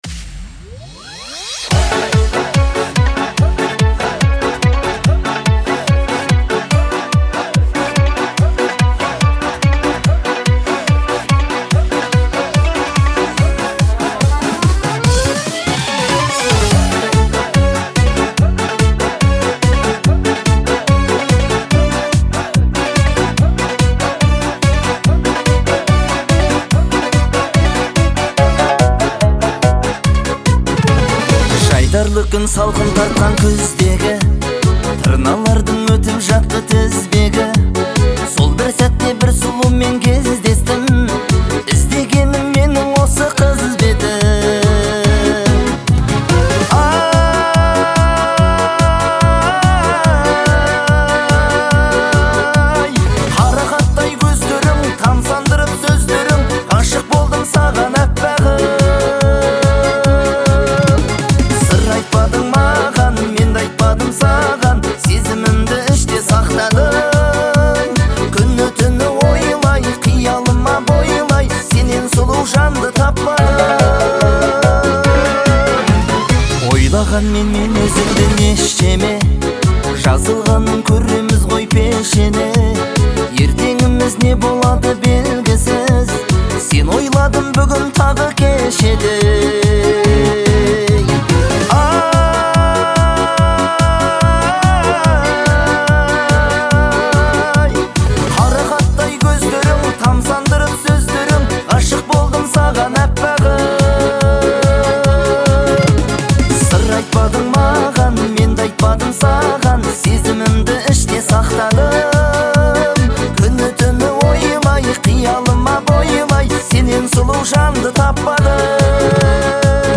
исполняет песню с искренностью